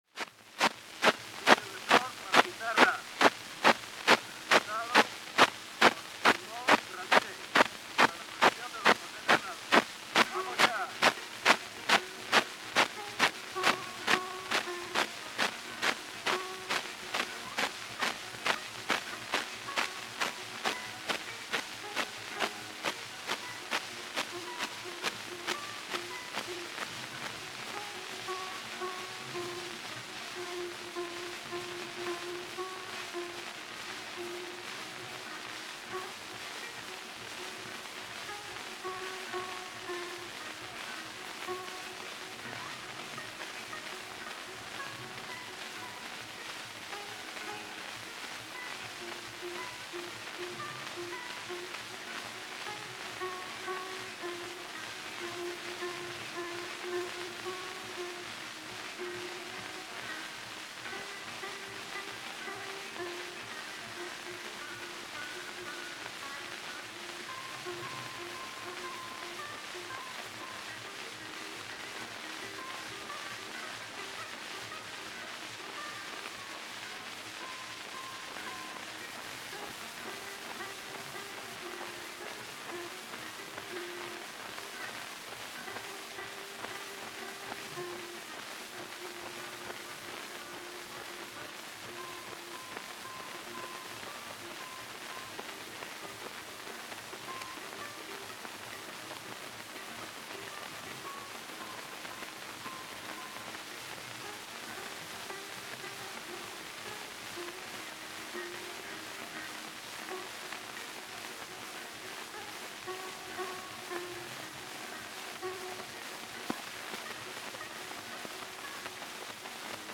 mentre la più antica incisione risulta realizzata a Madrid tra il 1897 e il 1901 su cilindro fonografico
ascoltarlo qui ma prestando moltissima attenzione e soprattutto tollerando i notevoli rumori di fondo con una pazienza ai limiti massimi.
essendo anch’egli un chitarrista